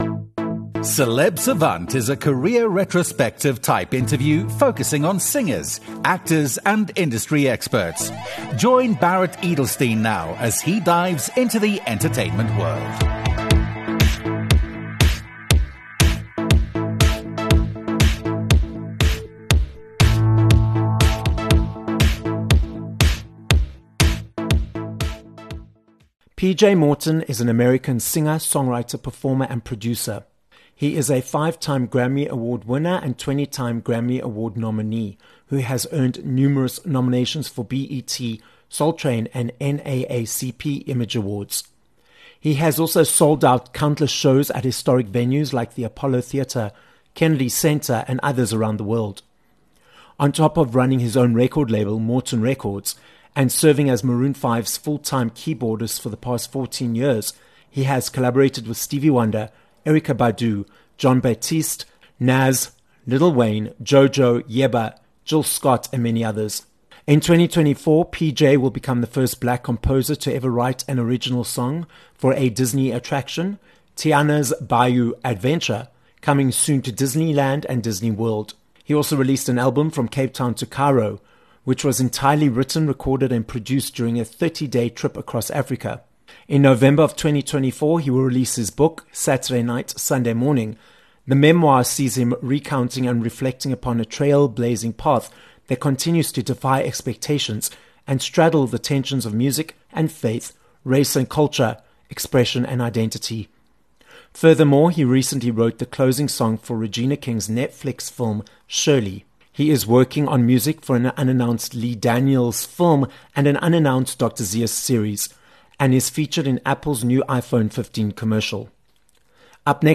PJ Morton - an American singer, songwriter, and producer who has won 5 Grammy Awards - joins us on this episode of Celeb Savant. We dive into PJ's various award-winning aspects of being in the music industry, which includes his solo work, working on projects for television and film, collaborating with Maroon 5 as their keyboardist, and all his latest projects.